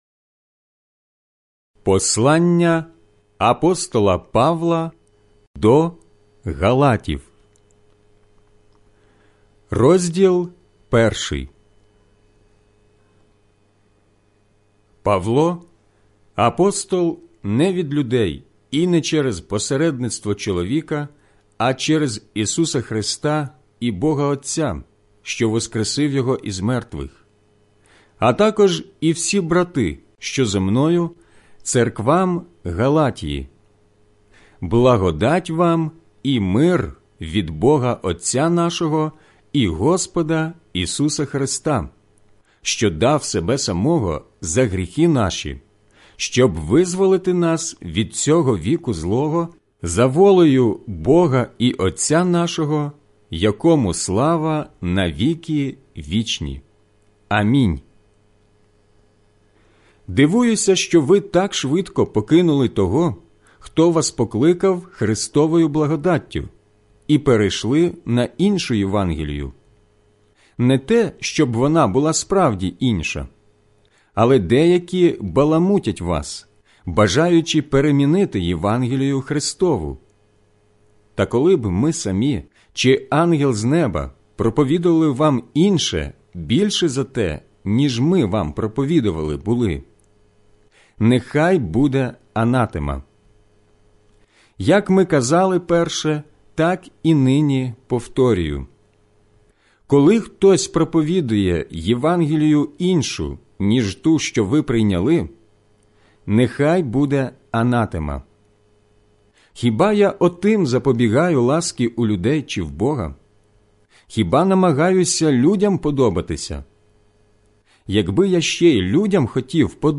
аудіобіблія